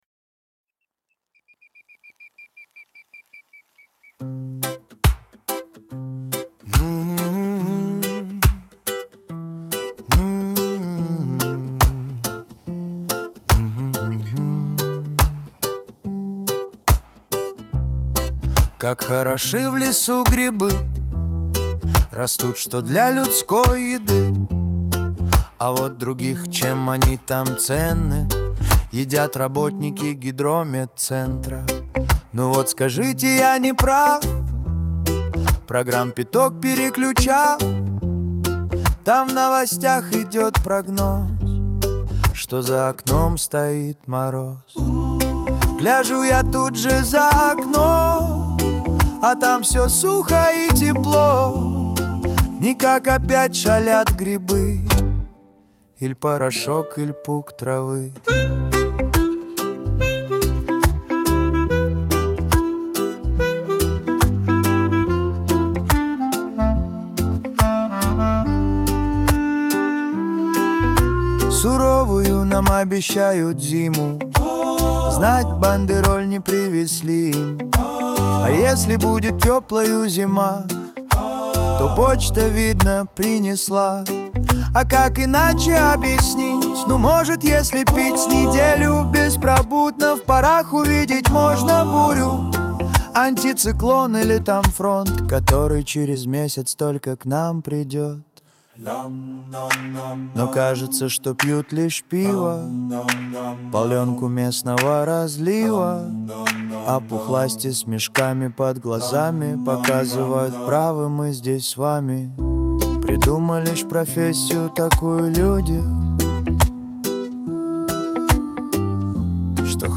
• Жанр: Регги